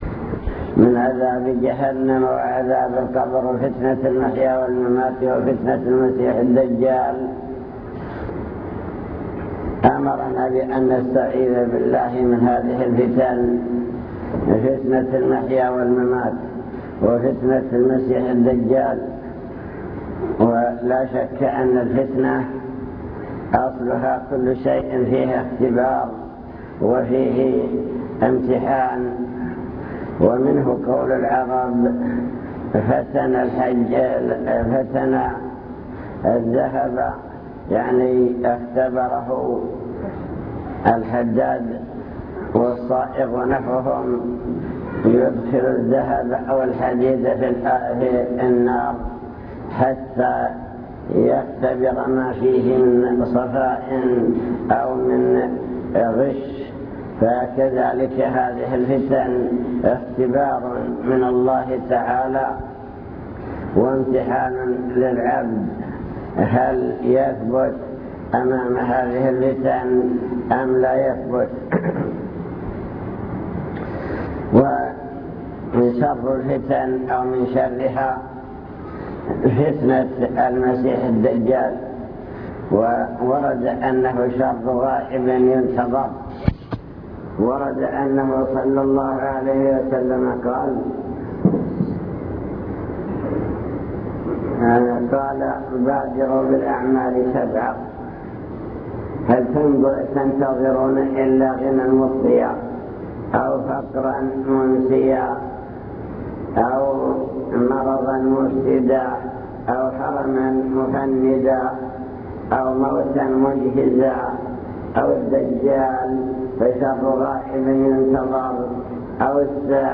المكتبة الصوتية  تسجيلات - محاضرات ودروس  محاضرة بعنوان شر غائب ينتظر